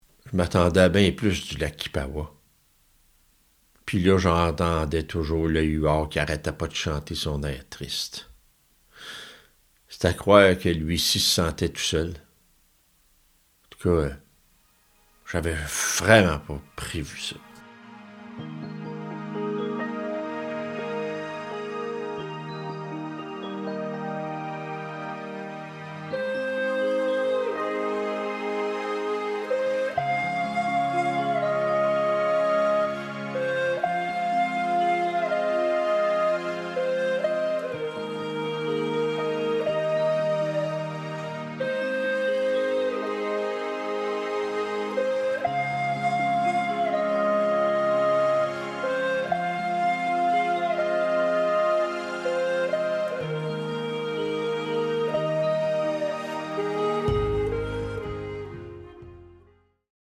Conte audio musical et immersif inspiré des années 1640
La musique soutient le récit, prolonge l’émotion et invite à une écoute profonde et consciente.